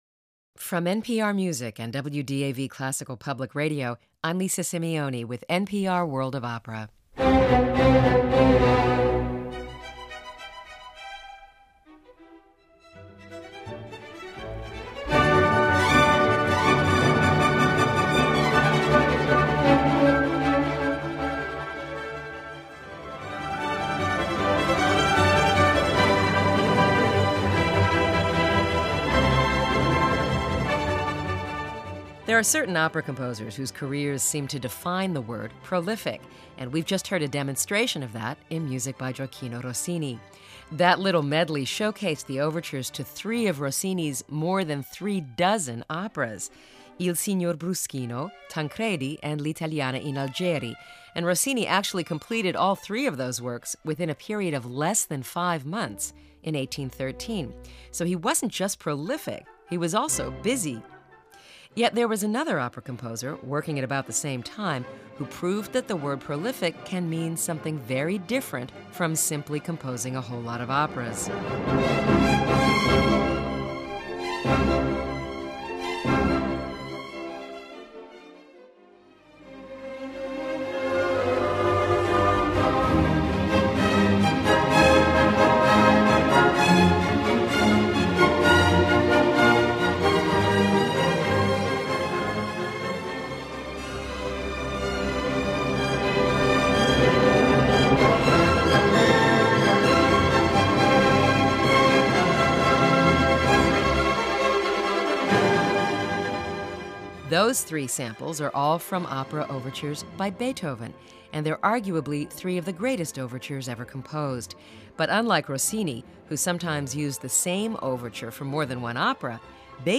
Beethoven's only opera is about a daring woman who dresses as a man, risking all to save her imprisoned husband. Read the story and hear excerpts from this Lucerne Festival production.
Claudio Abbado conducts this concert performance of Beethoven's "Fidelio" at the Lucerne Festival in Switzerland.